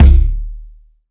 Kick (99).wav